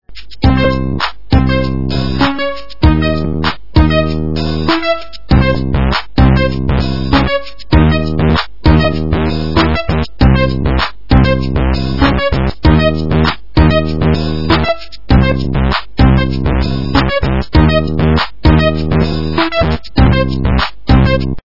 - рэп, техно